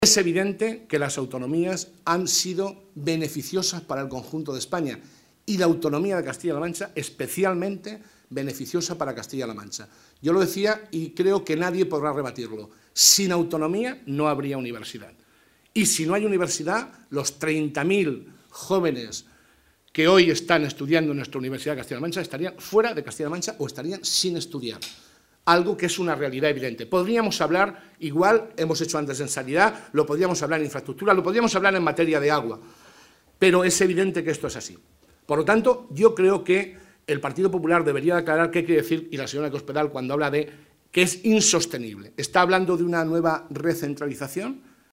El portavoz del Grupo Parlamentario Socialista, José Molina, ha destacado, en una rueda de prensa en Ciudad Real, que el Gobierno de Castilla-La Mancha acometerá un “esfuerzo importante” a través del Plan de Consolidación de las Cuentas Públicas, aprobado esta misma mañana por el Consejo de Gobierno.